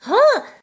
daisy_panting_cold.ogg